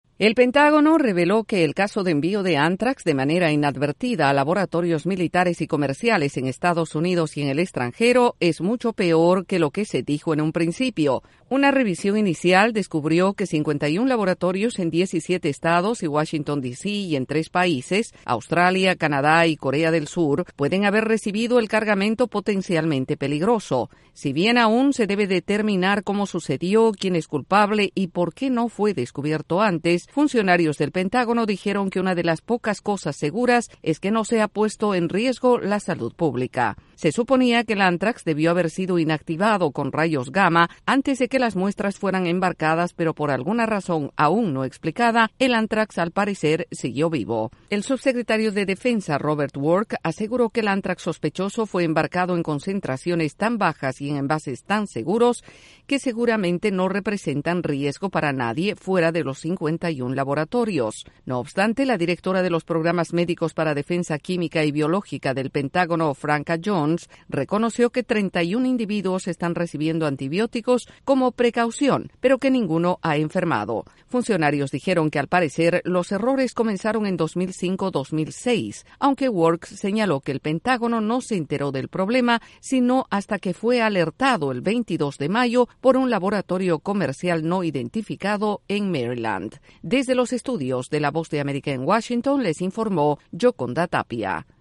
El Departamento de Defensa admitió que los envíos de ántrax vivo son mucho peor de lo que se pensó inicialmente pero advierten que no hay peligro para la salud pública. Desde la Voz de América en Washington informa